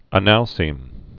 (ə-nălsēm) also a·nal·cite (-sīt)